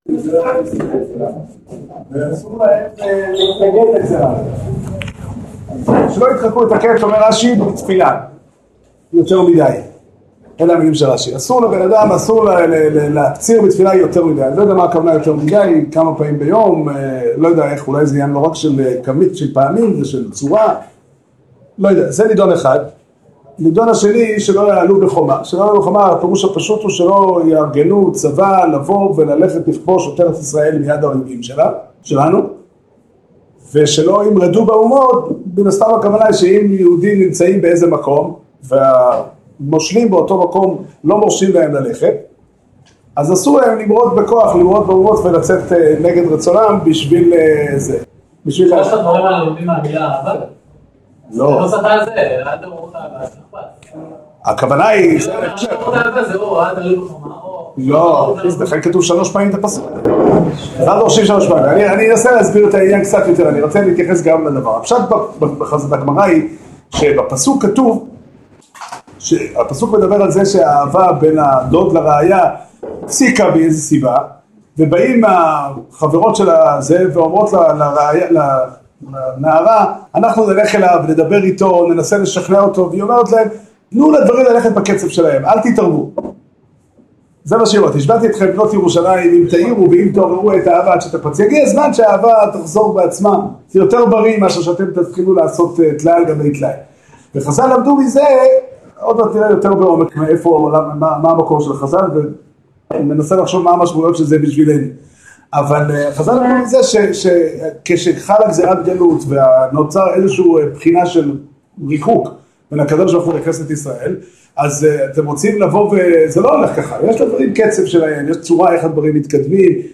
שיעור שנמסר בישיבת דרך ה' בתאריך ה' אב תשע"ו